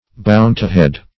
Search Result for " bountihead" : The Collaborative International Dictionary of English v.0.48: Bountihead \Boun"ti*head\, Bountyhood \Boun"ty*hood\, n. Goodness; generosity.